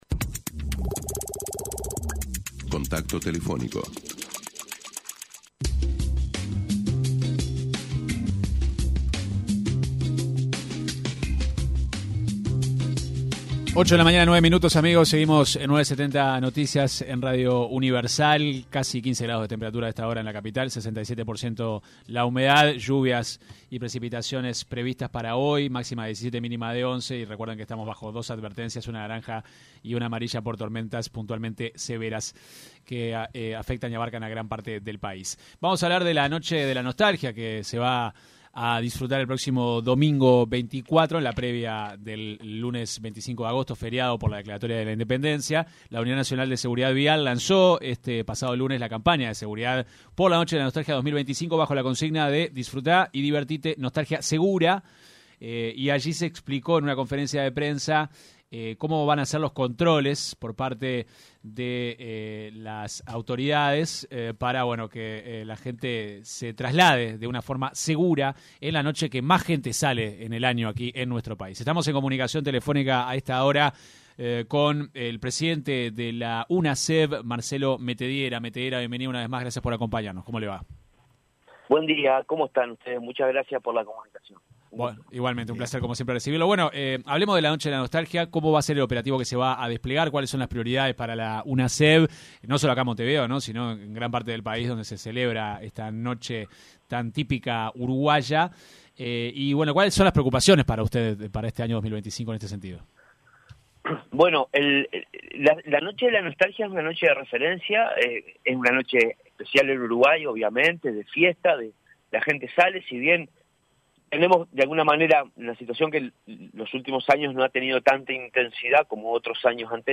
El presidente de la Unidad Nacional de Seguridad Vial (Unasev), Marcelo Metediera, se refirió en entrevista con 970 Noticias, al tema de los radares pedagógicos y la polémica de la aplicación de multas ya no va a ser aplicable ante la falta del mismo.